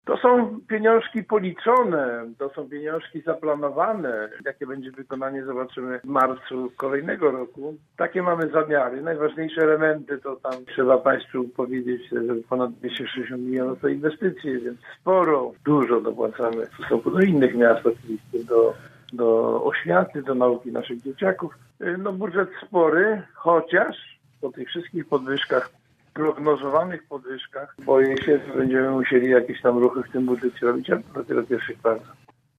Dziś o przyszłorocznym budżecie miasta w „Rozmowie po 9” mówił radny Andrzej Bocheński, z klubu Zielona Razem: